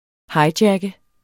Udtale [ ˈhɑjˌdjagə ]